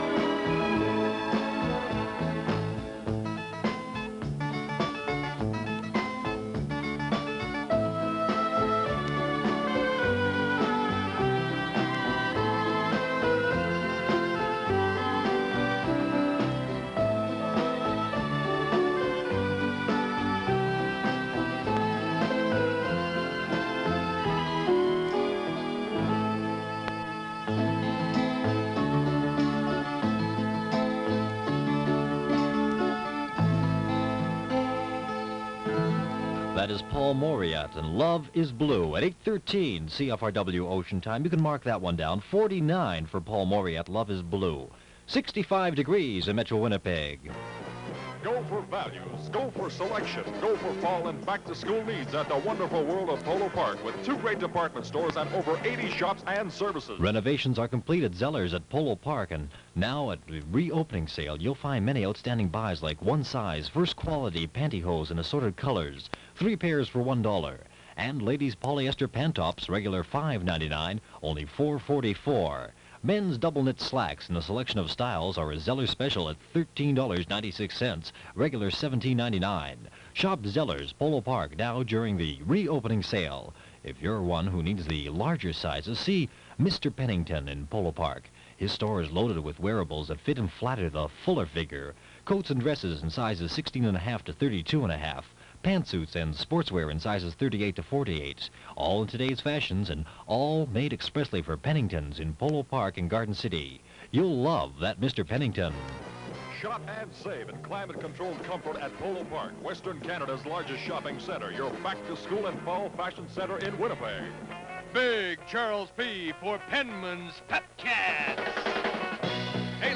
Winnipeg Radio in 1971
- Bobby Bloom Sings about Pepsi Cola.